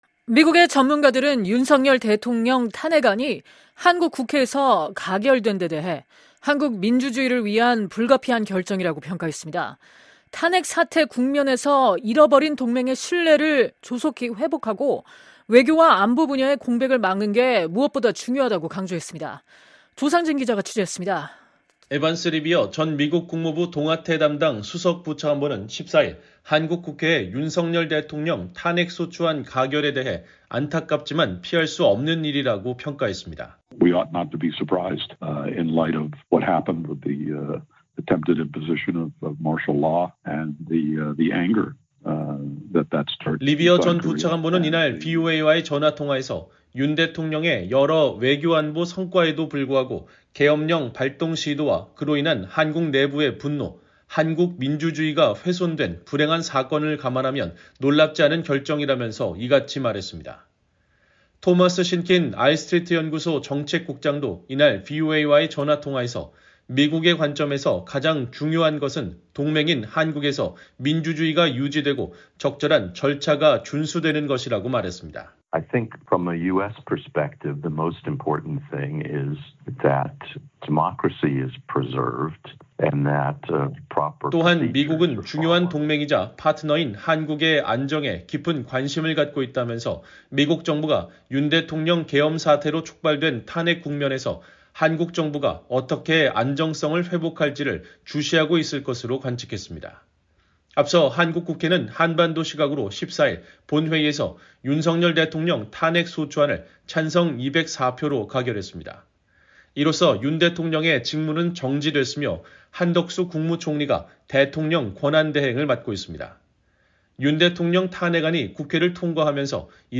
리비어 전 부차관보는 이날 VOA와의 전화통화에서 윤 대통령의 여러 외교 안보 성과에도 불구하고 “계엄령 발동 시도와 그로 인한 한국 내부의 분노, 한국 민주주의가 훼손된 불행한 사건을 감안하면 놀랍지 않은 결정”이라면서 이같이 말했습니다.